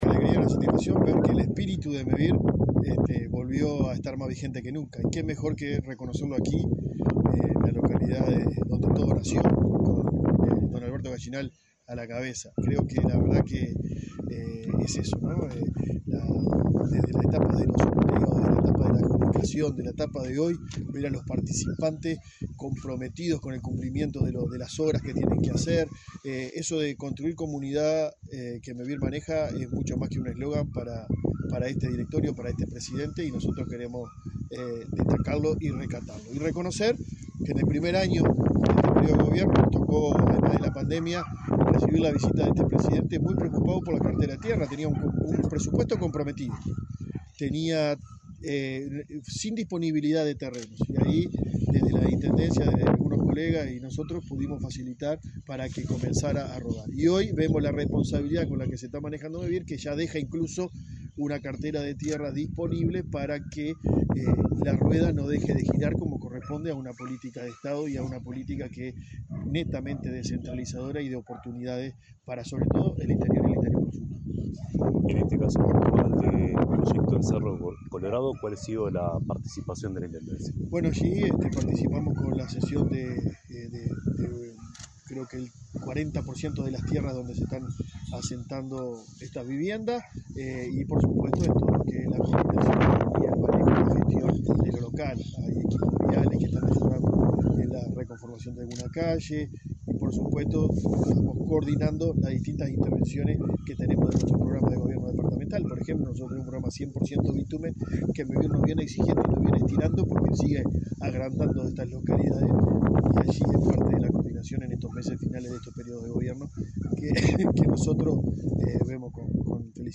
Entrevista al intendente de Florida, Guillermo López